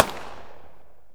mp5k_dist.wav